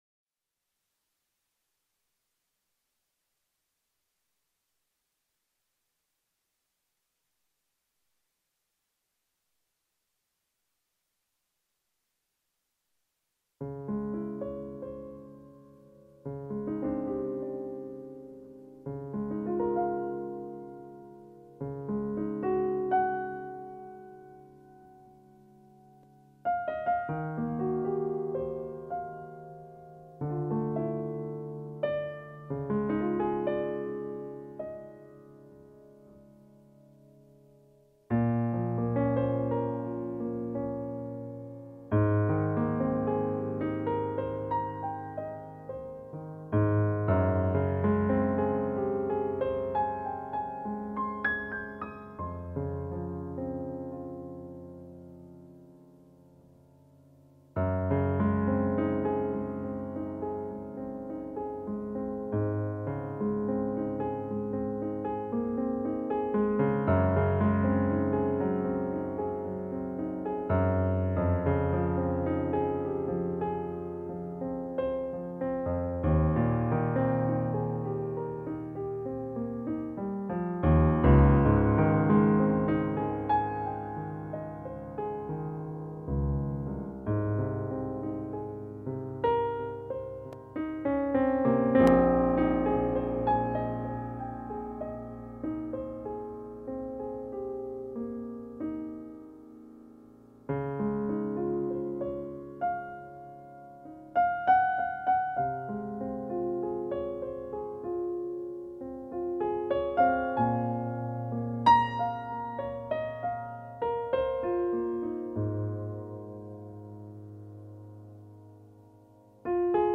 My Feelings During tonight’s session, I felt a sense of power and certainty.
If you missed the live stream, take a moment to listen now. 15th FeelYourself Download I’d love to hear your thoughts and feelings—share them in the comments!